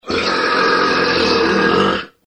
Quite Possibly the Loudest Burp Ever at 117.9 Decibels